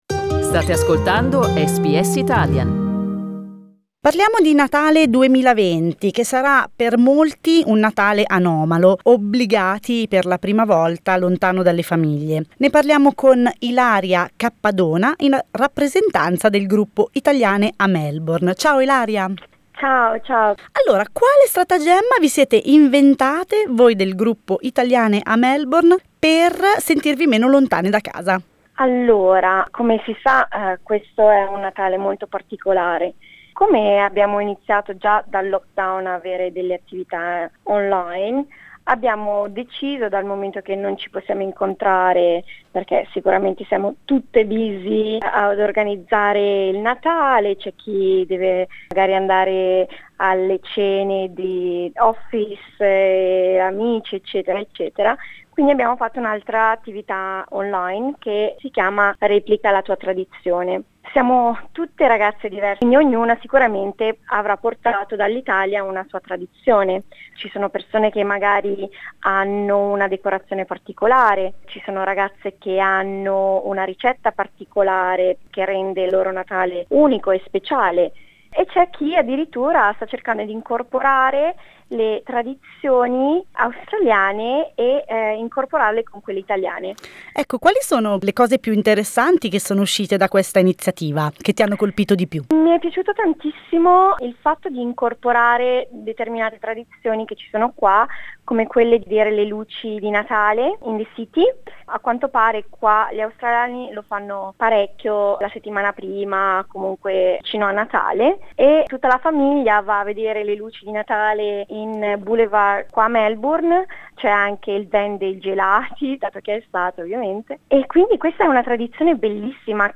Ascolta l'intervista: LISTEN TO Condividere le tradizioni natalizie ci fa sentire meno lontani da casa SBS Italian 07:00 Italian Le persone in Australia devono stare ad almeno 1,5 metri di distanza dagli altri.